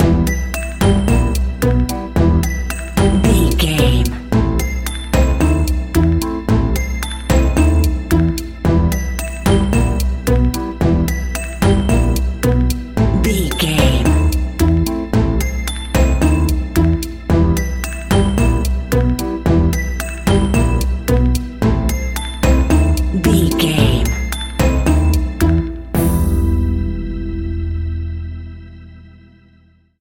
Aeolian/Minor
ominous
eerie
piano
drums
synthesizer
spooky
horror music
Horror Pads